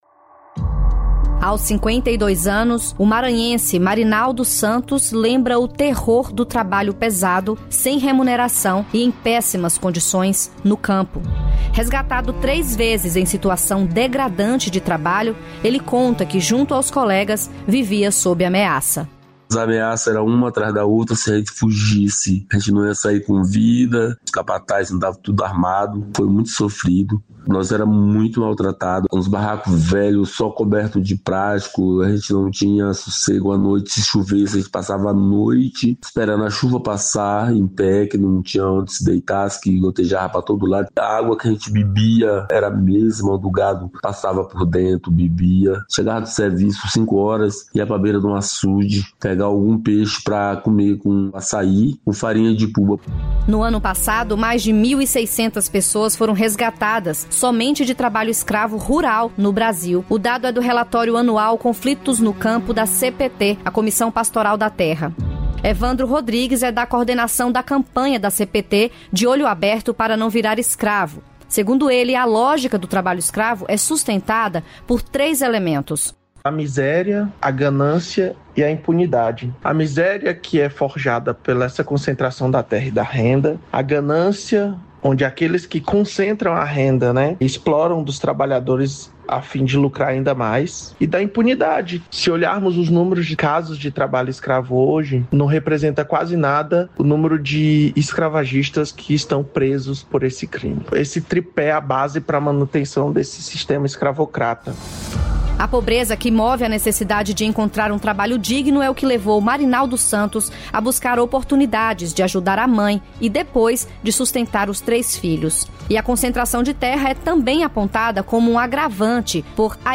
Trabalho escravo: homem resgatado relata condições degradantes